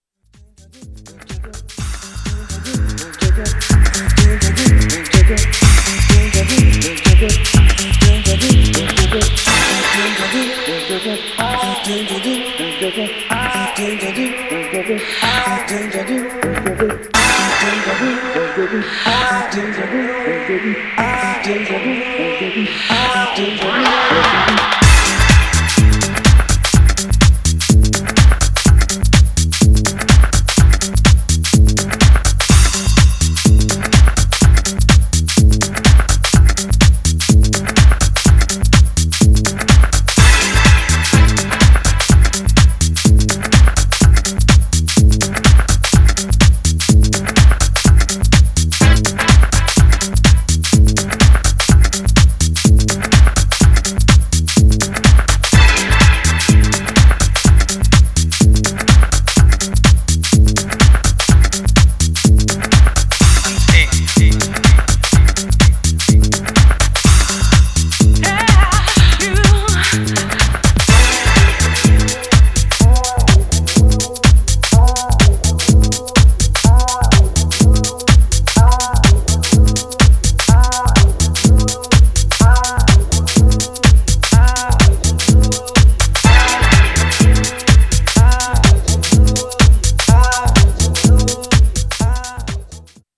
Styl: Progressive, House